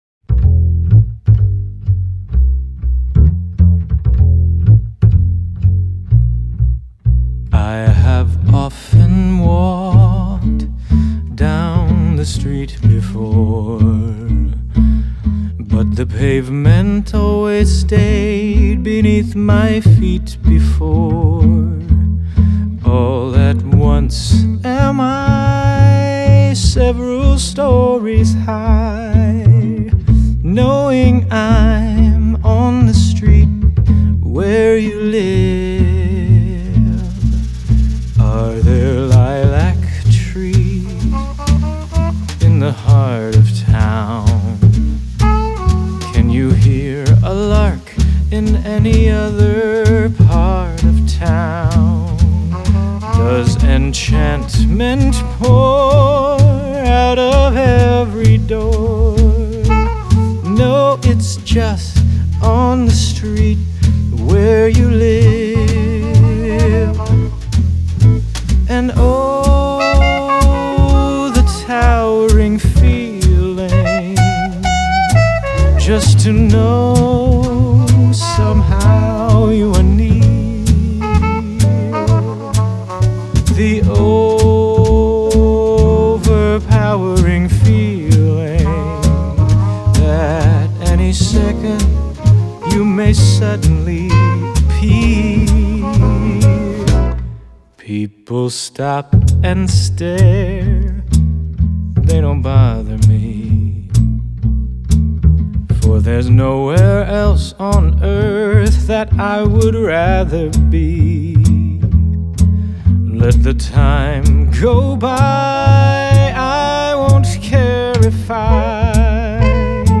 1956   Genre: Musical   Artist